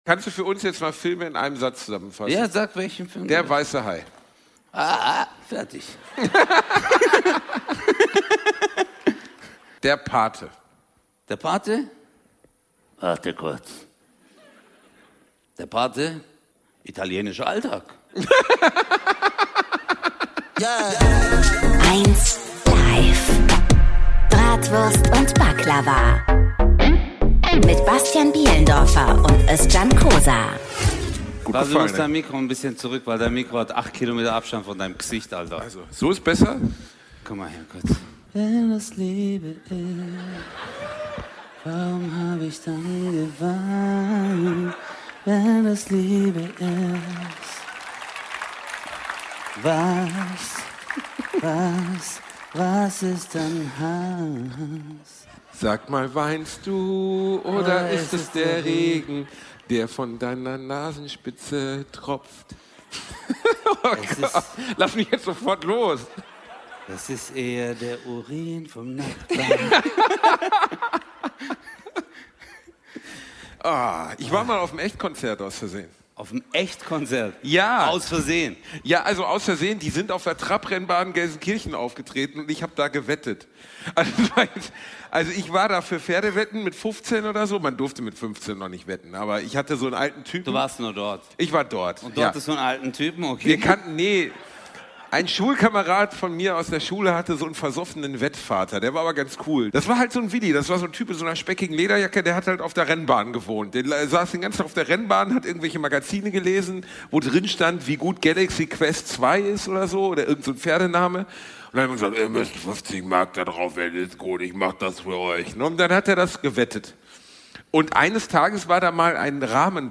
#108 Stabile Seitenlage LIVE in Rietberg 2 ~ Bratwurst und Baklava - mit Özcan Cosar und Bastian Bielendorfer Podcast